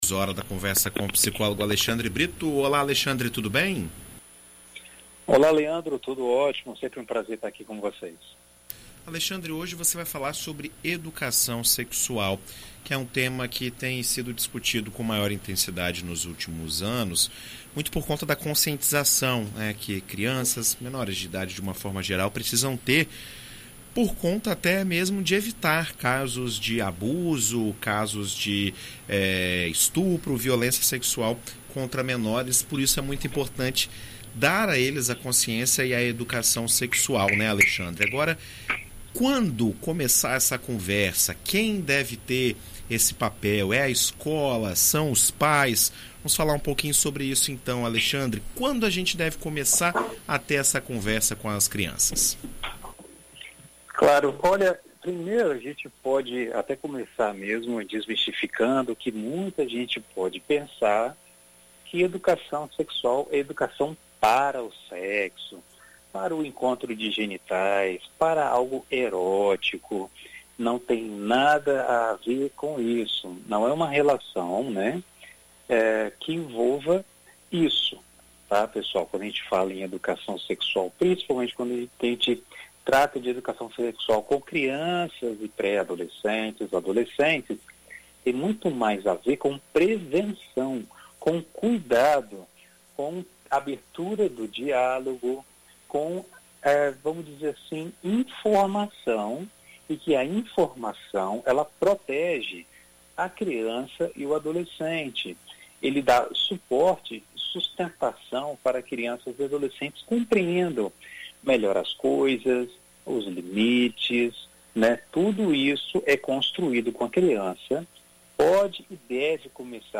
Em entrevista à BandNews FM ES nesta segunda -feira (08)